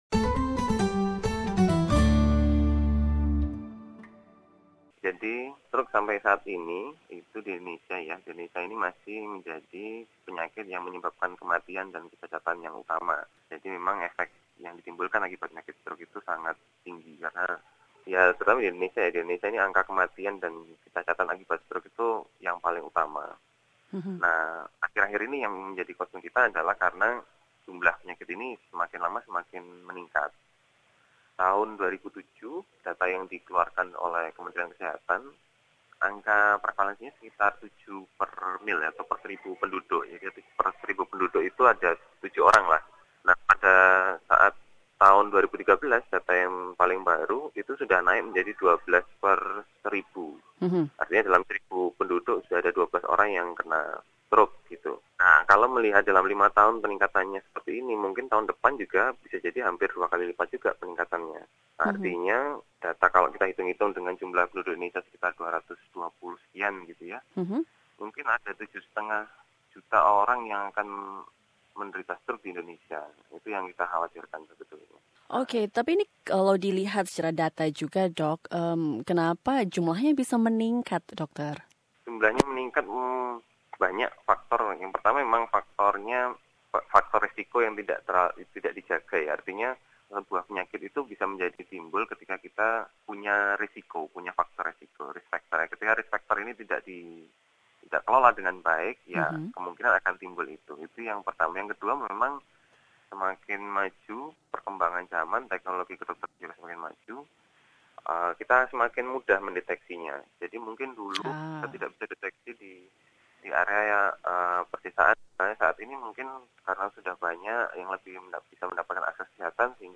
In the light of World Stroke Day on October 29, SBS Radio talks to a neurologist